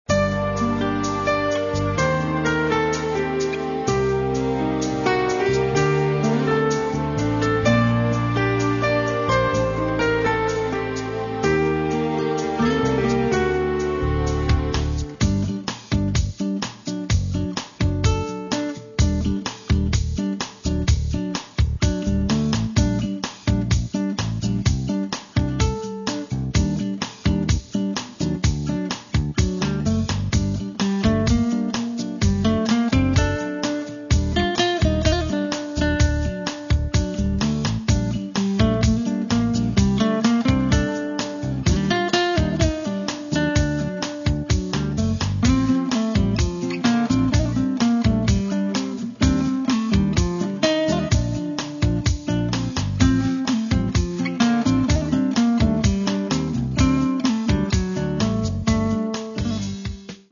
Catalogue -> Variety Art -> Instrumental Variations